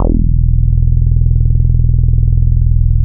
RESO BASS 2.wav